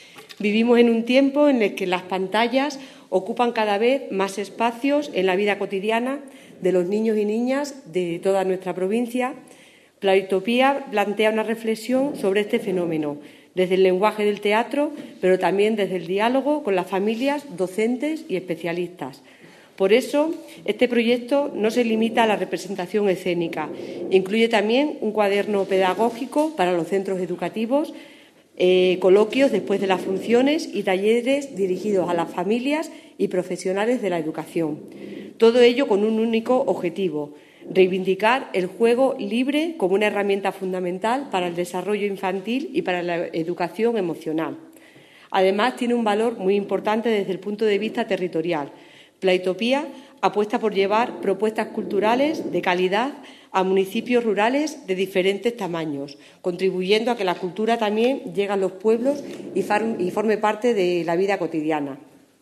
han presentado en rueda de prensa el proyecto Playtopía o el derecho jugar”.